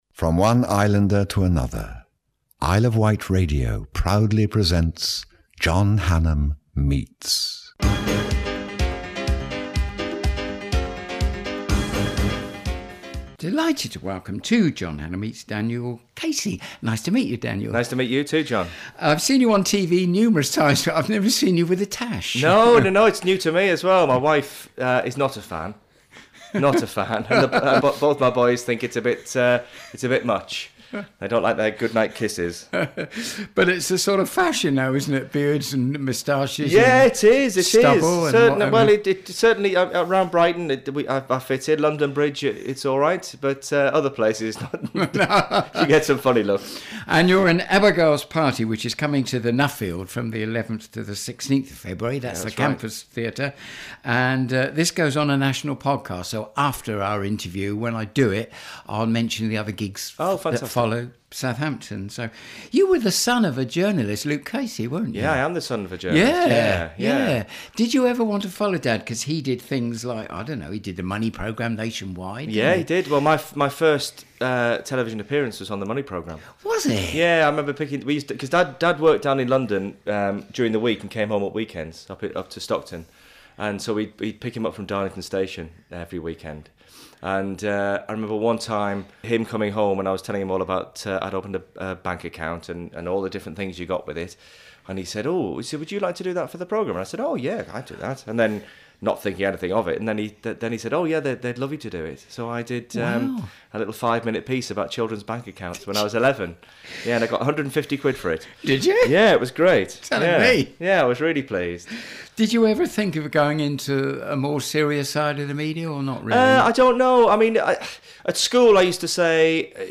interviews celebrities on their life and work.